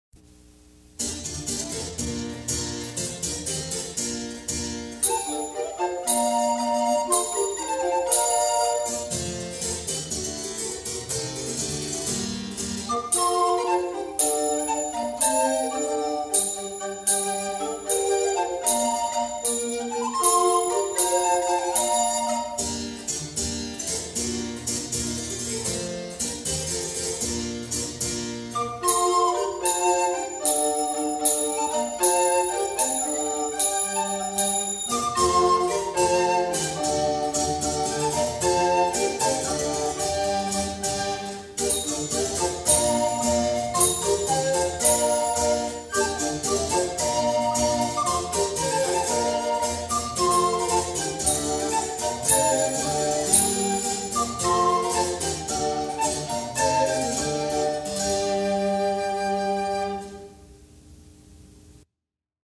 | Instrumental Ensemble, 'Royal Court of Cracow' Session 1973